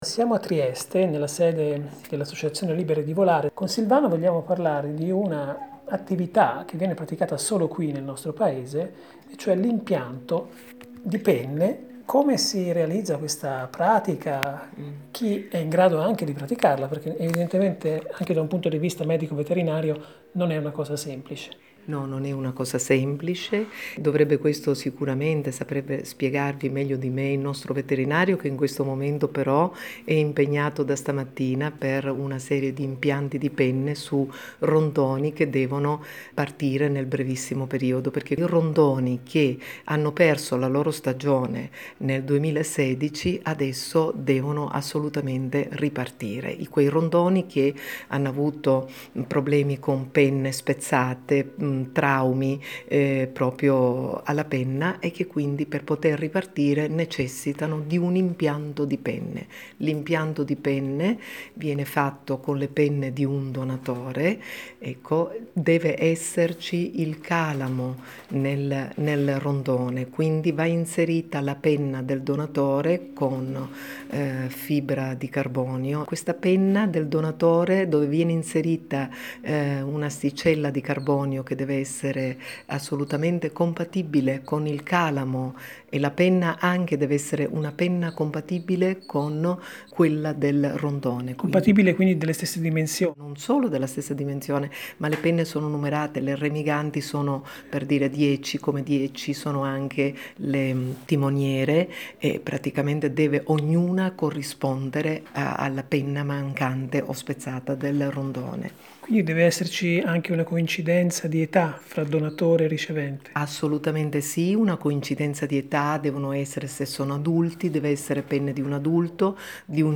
20/5/17 – A Trieste l’impianto delle penne nei rondoni / INTERVISTA